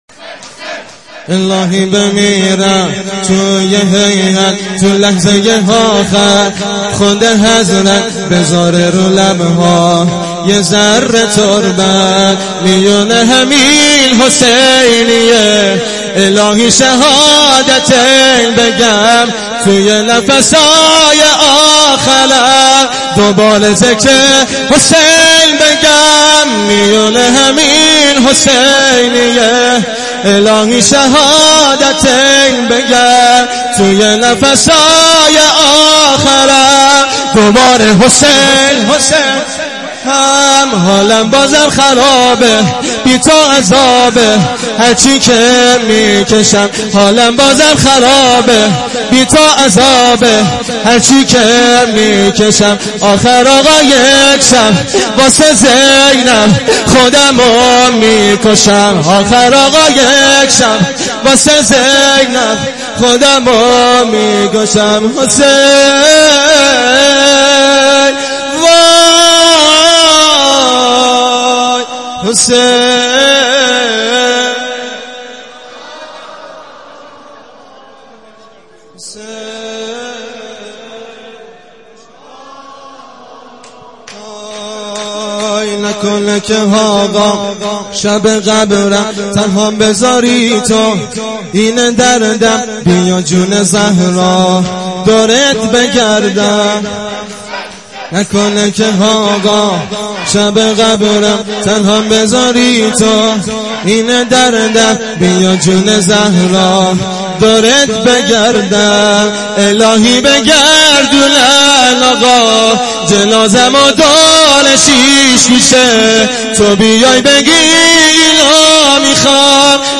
شور امام حسین ع